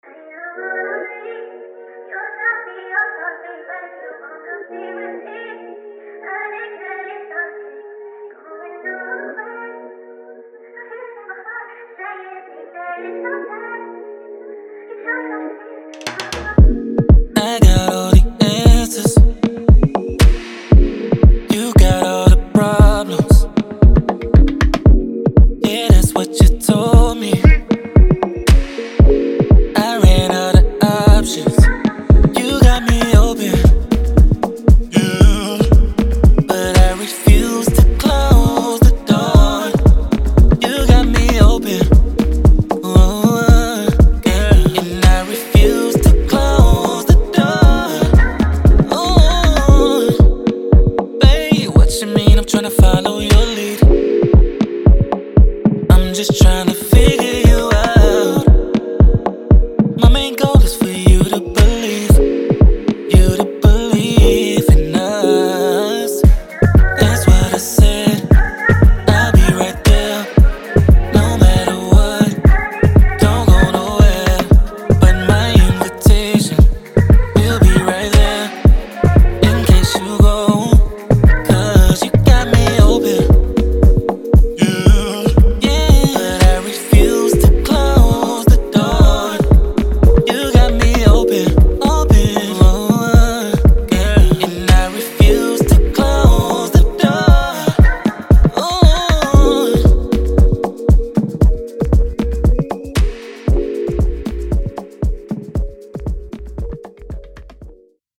Afrobeat
D# Minor